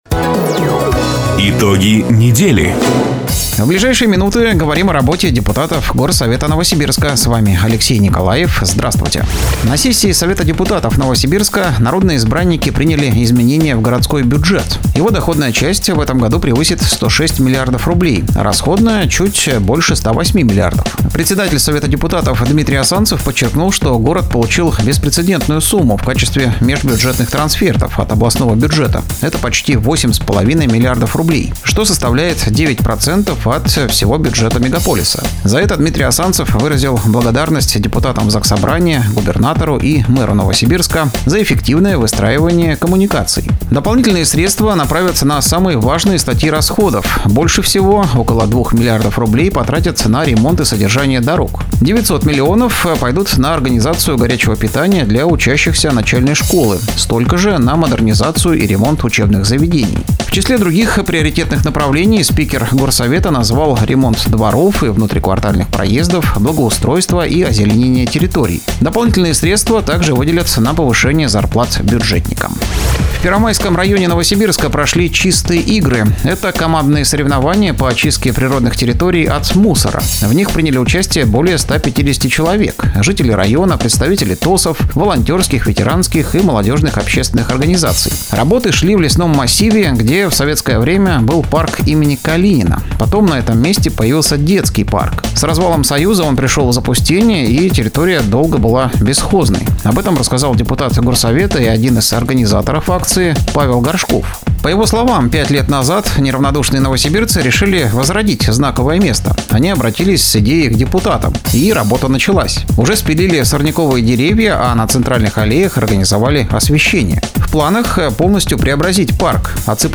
Запись программы "Итоги недели", транслированной радио "Дача" 31 мая 2025 года